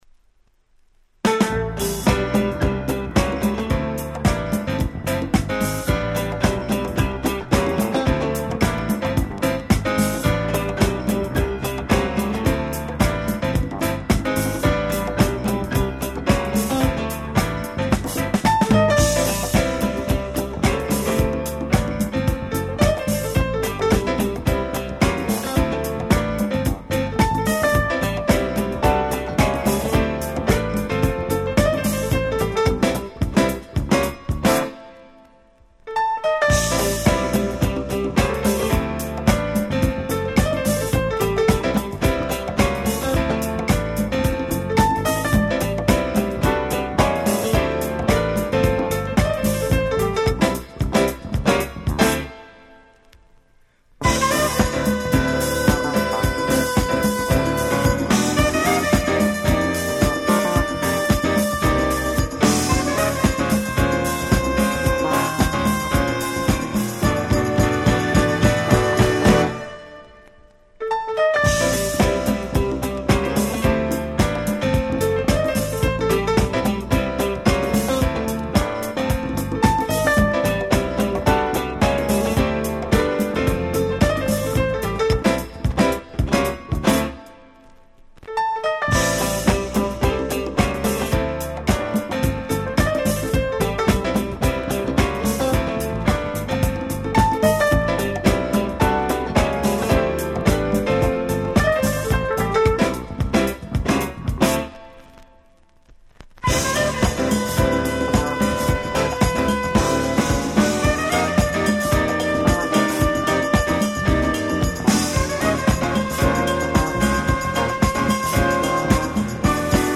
音質もバッチリだし使い易いしで海外でも非常に人気で安定した価格のシリーズです！